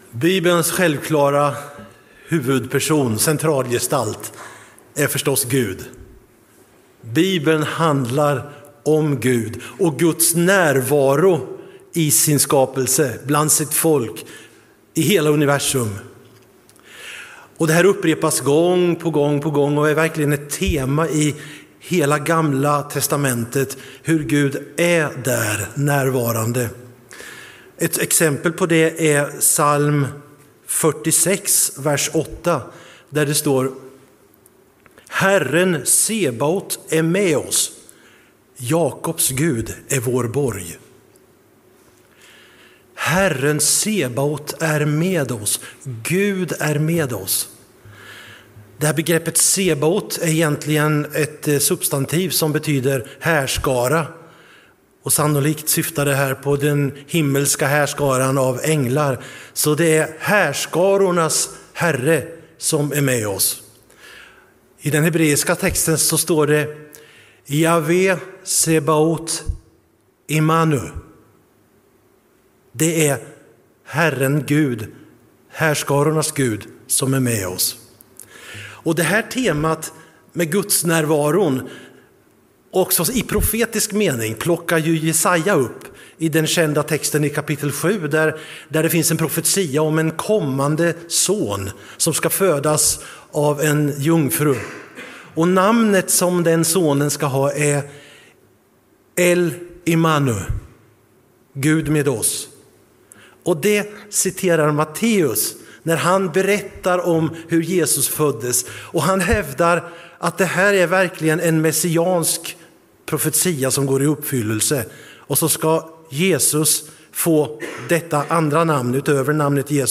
Gudstjänst i Centrumkyrkan Mariannelund.